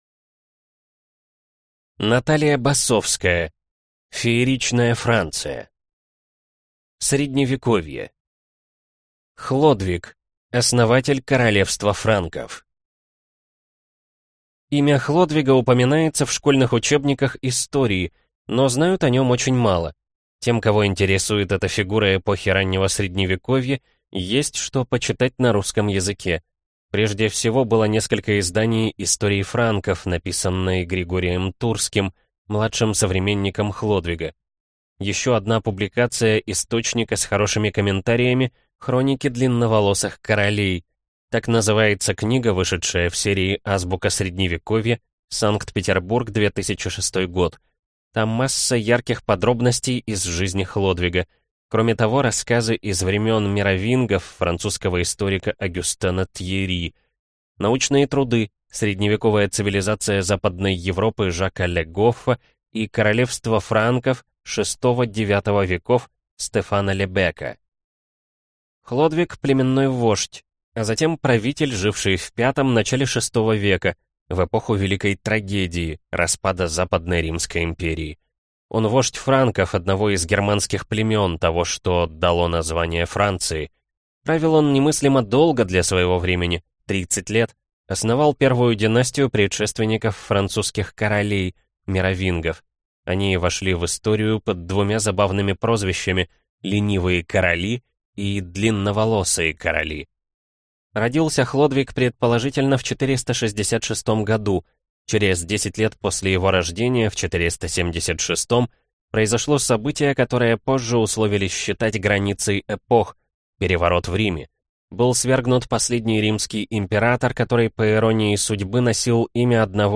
Aудиокнига Фееричная Франция